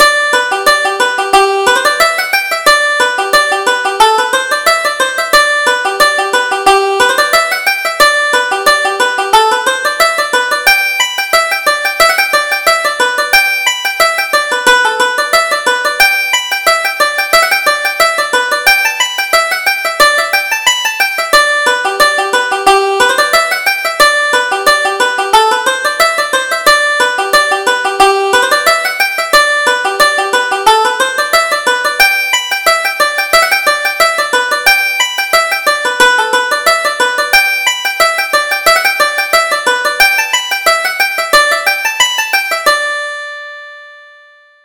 Reel: Come West Along the Road